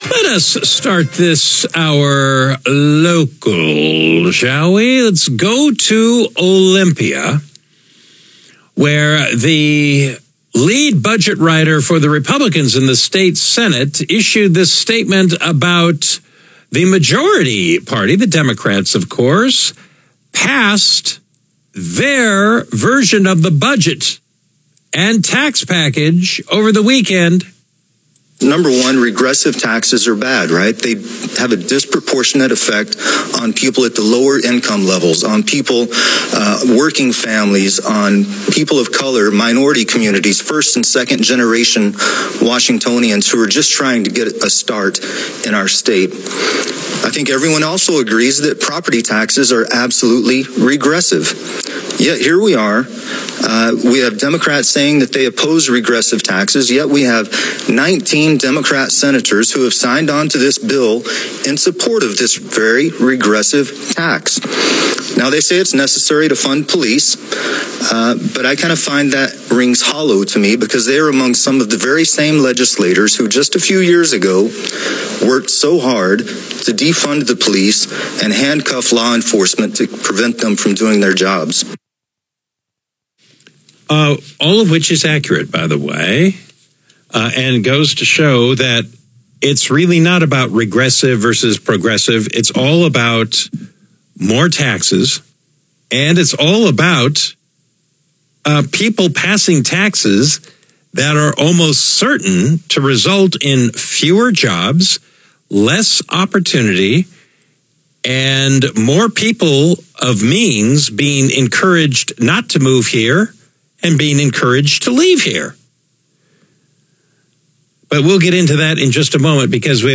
Sen. John Braun discussed tax relief, public safety, and government accountability on KVI Radio.